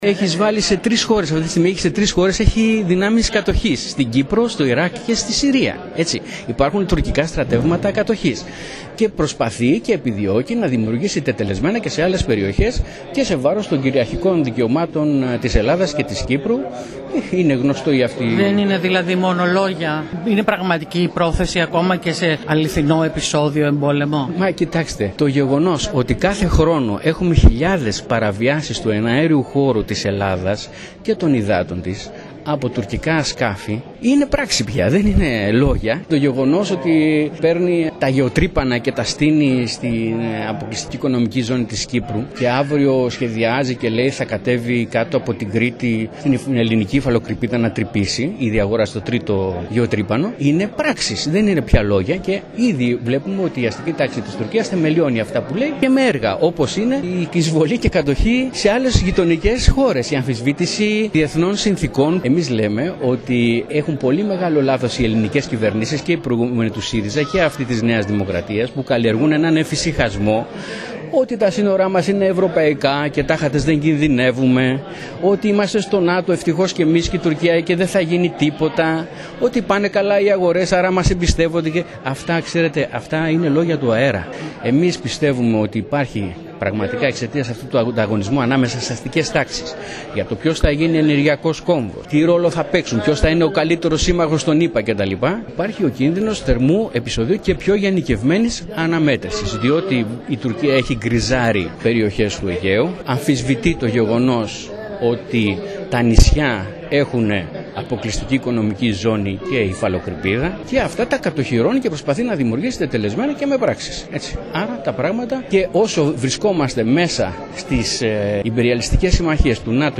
Κέρκυρα: Η εκδήλωση του ΚΚΕ για τις διεθνείς σχέσεις